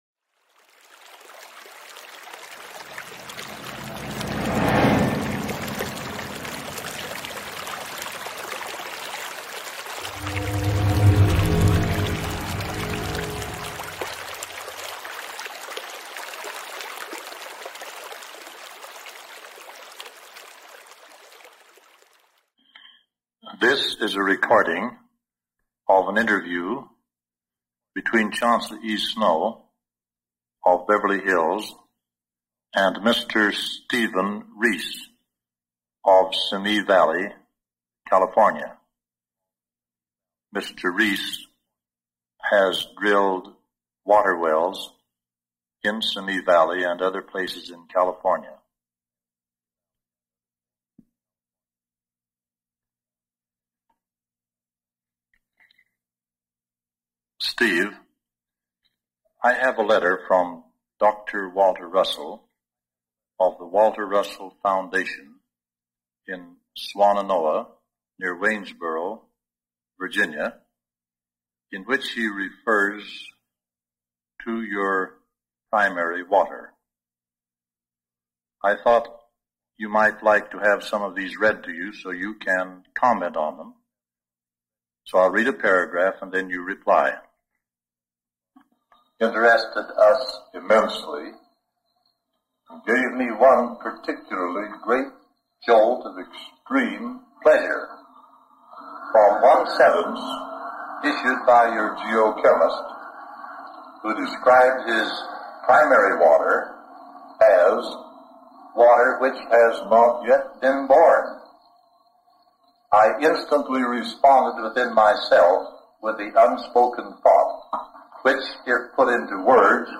This previously never-before-released recording was found in the USP audio archive and sheds invaluable light on the similarities between the work of two great men. What is presented here is a reel-to-reel tape recording of a letter written by Dr. Walter Russell